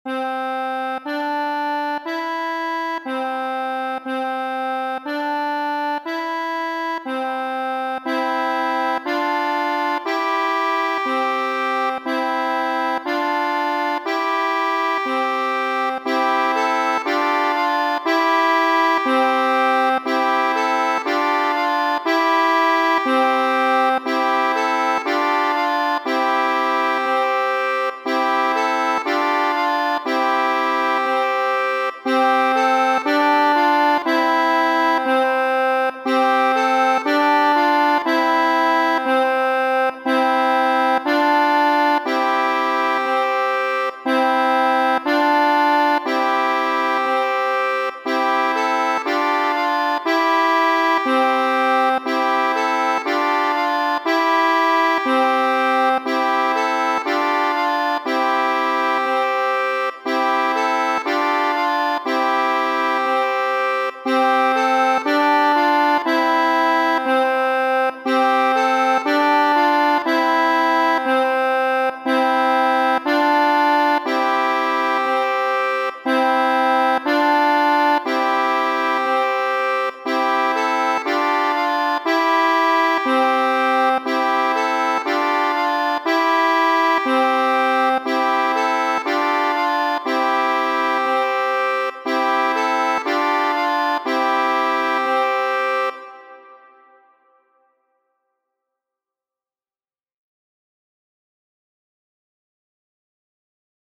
Bone, nun ni bezonas alian kantiston, kaj kiam ni atingas la trian mezuron, li aŭ ŝi komencu kanti la komencon, kaj ni havas duvoĉan kanton Frere Jacques: